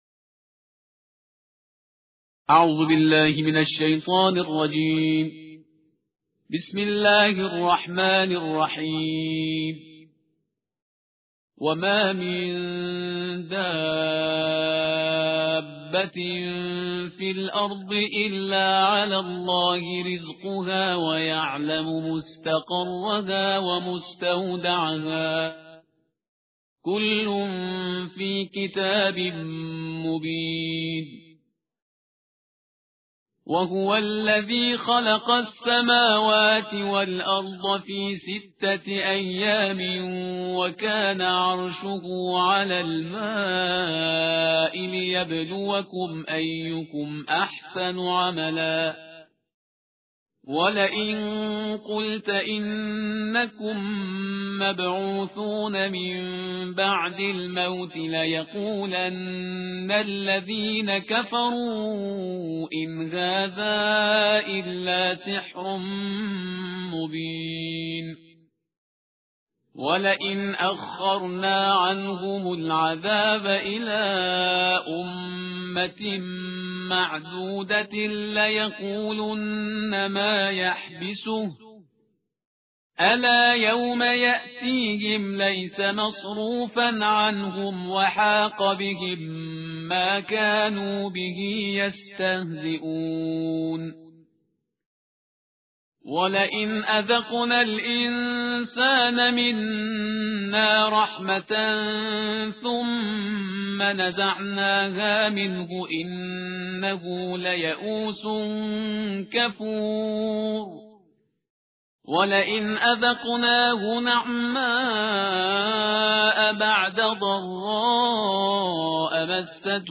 ترتیل جزء دوازده قرآن کریم/استاد پرهیزگار